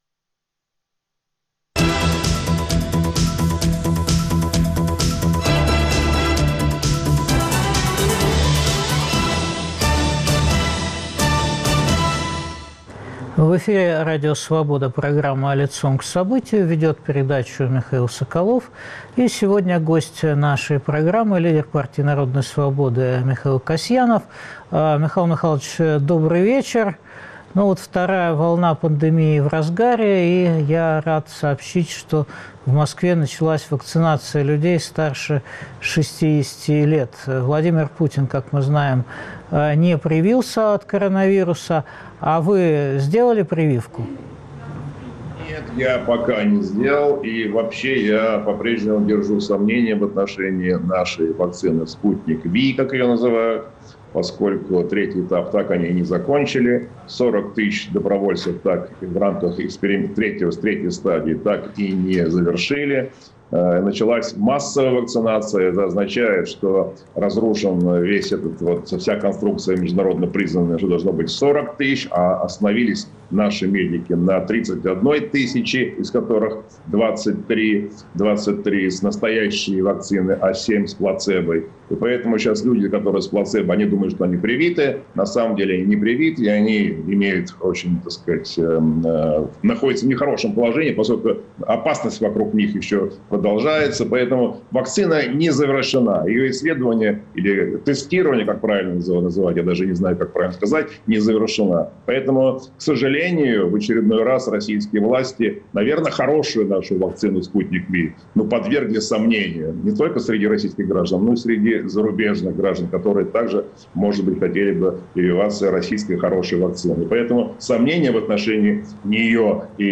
Что ждет Россию после обнуления и пандемии? Перспективы 2021 года обсуждаем с главой Партии народной свободы Михаилом Касьяновым.